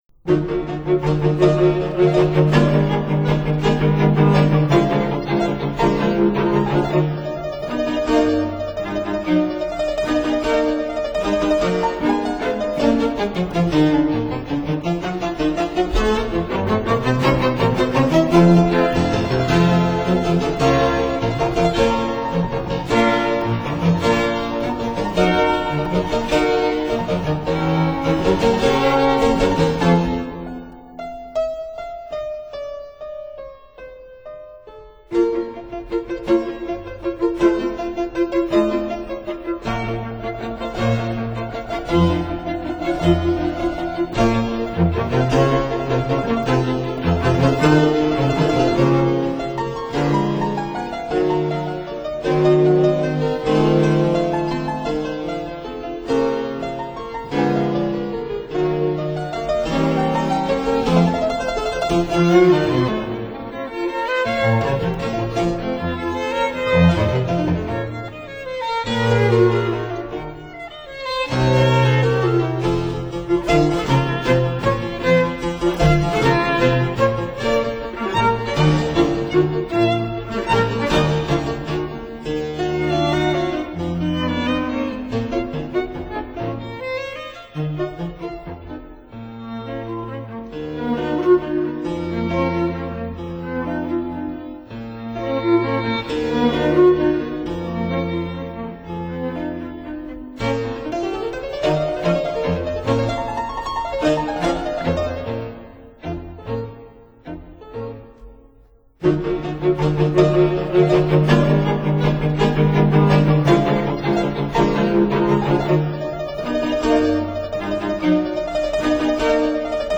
Piano Quartet in E-flat major
Violin
Viola
Cello
Fortepiano
(Period Instruments)